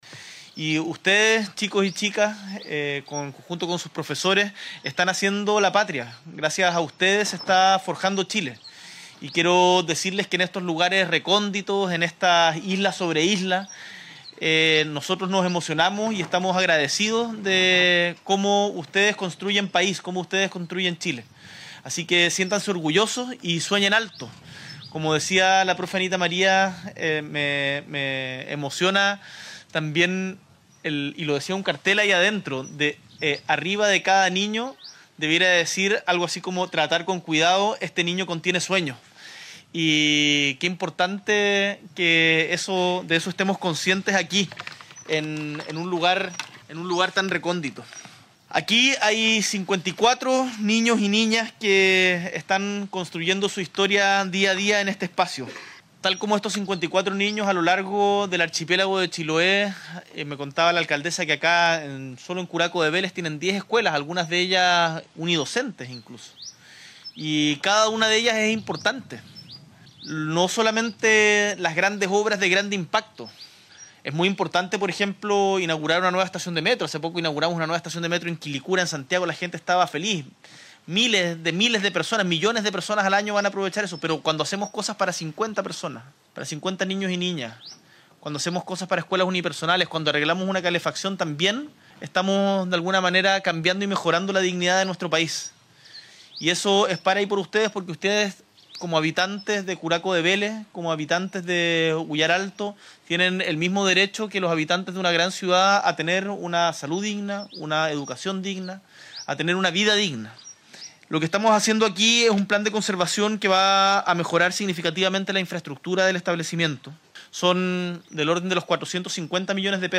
El Presidente de la República, Gabriel Boric; el ministro de Educación, Nicolás Cataldo, y la alcaldesa de Curaco de Vélez, Javiera Yáñez, encabezaron este miércoles 31 de enero la ceremonia de inicio de las obras de conservación de la escuela rural del sector de Huyar Alto, un proyecto que beneficiará a 54 alumnos y alumnas y toda la comunidad educativa, con una inversión de casi $450 millones.
Al respecto, el Mandatario y tras firmar el acta de inicio de las faenas, señaló:
PRESIDENTE-GABRIEL-BORIC-.mp3